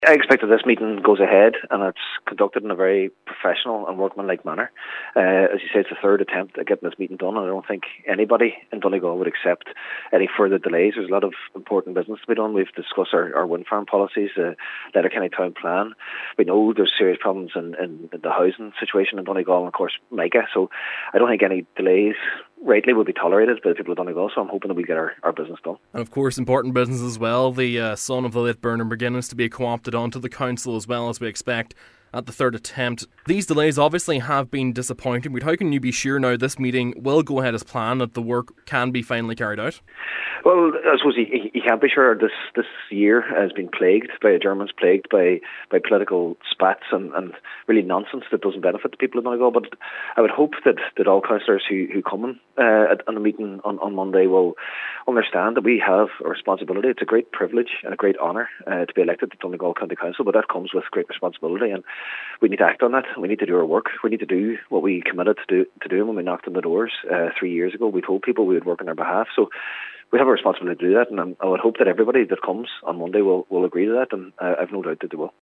Cathaoirleach Cllr Jack Murray says he expects the meeting will go ahead without delay today: